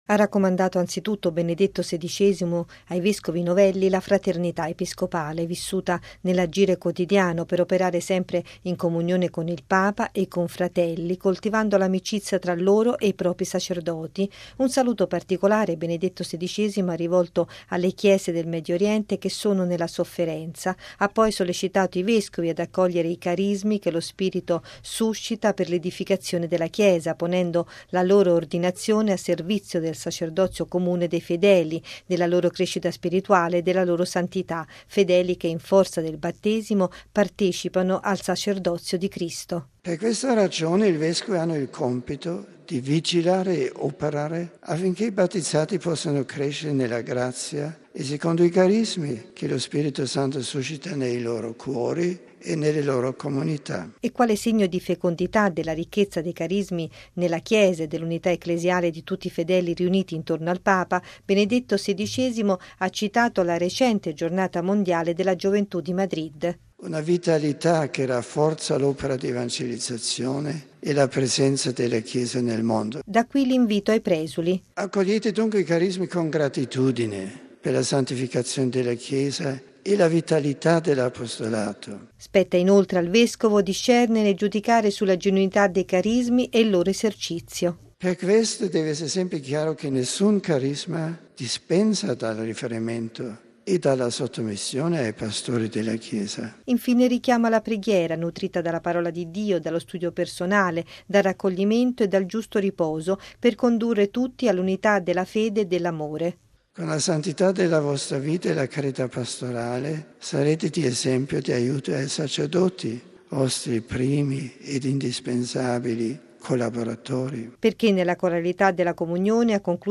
◊   Il vescovo “non è un uomo solo”, ha ricordato il Papa ai presuli di recente nomina, ricevuti stamane nel Cortile del Palazzo apostolico di Castel Gandolfo.